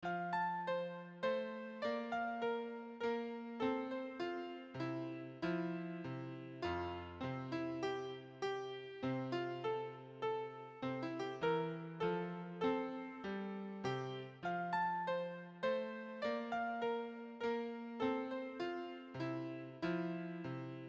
Piano Classique